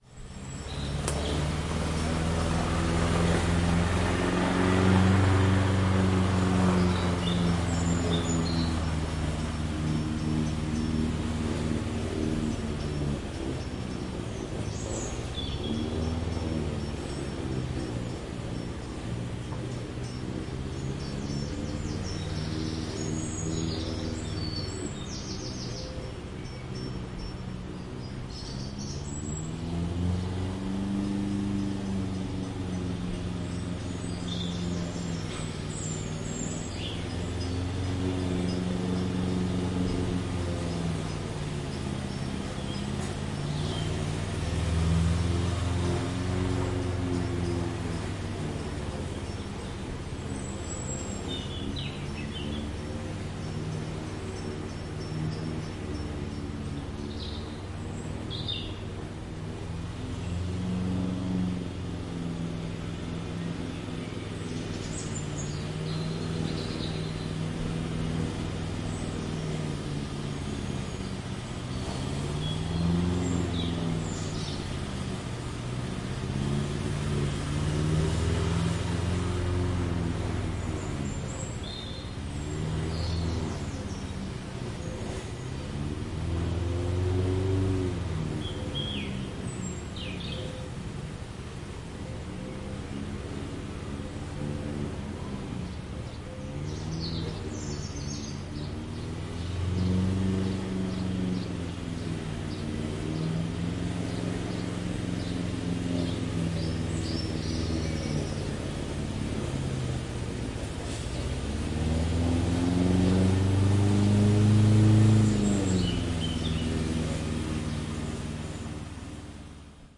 汽油割草机
描述：汽油割草机在割草时来回推动。
Tag: 草坪 割草机